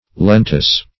Search Result for " lentous" : The Collaborative International Dictionary of English v.0.48: Lentous \Len"tous\ (-t[u^]s), a. [L. lentus.